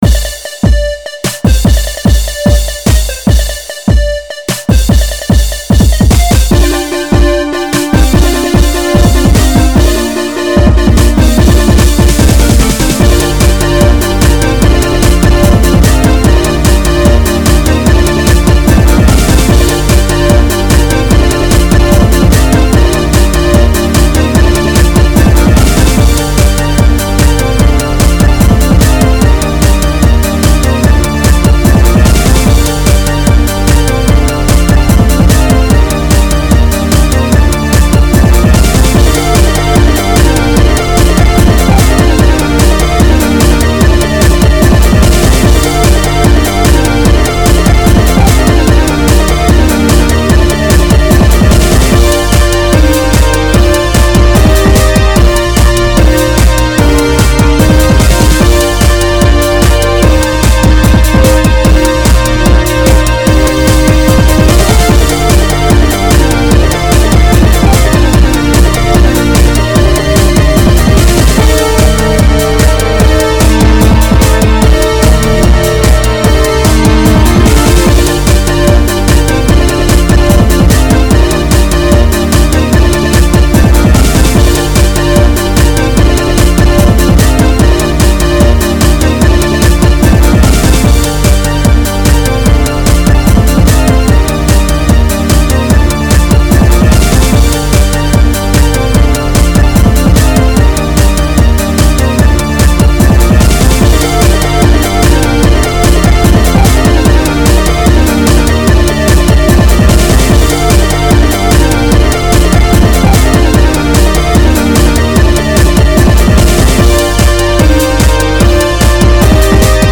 モノラル   テクノサウンドのバトル曲です。
高音部がちょっと耳に痛いかもしれません＞＜